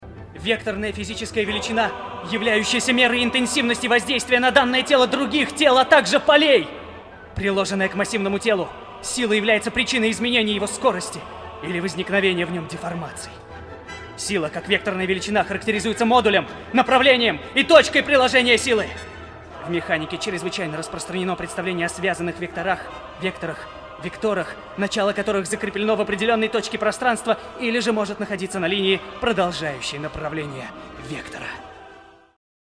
И сразу: мне попался сериал в дубляже.
Разве не очевидно, что его «подростковый» голос с образом Малдера, да и с самим Дэвидом Духовны, сочетается более чем никак?